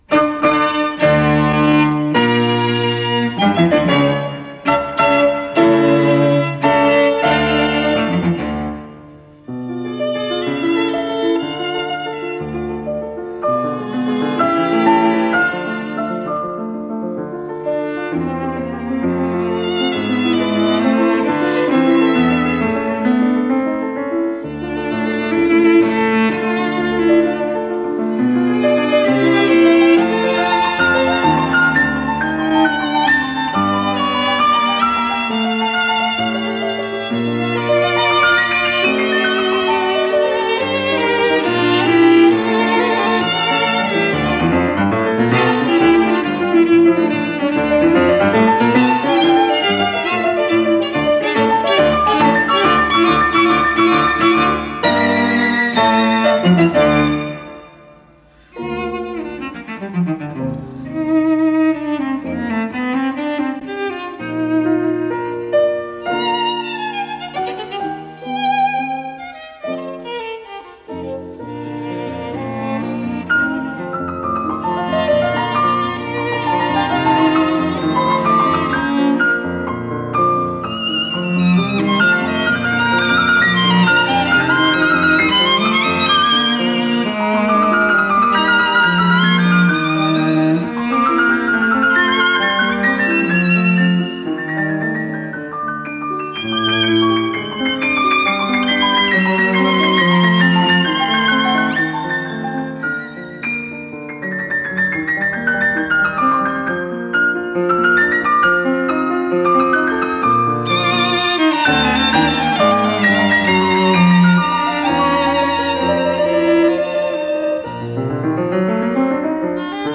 Исполнитель: Игорь Жуков - фортепиано Григорий Фейгин - скрипка Валентин Фейгин - виолончель
для фортепиано, скрипки и виолончели
ре минор